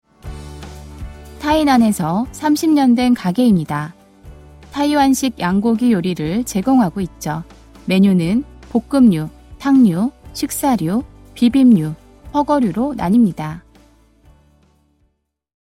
한국어 음성 안내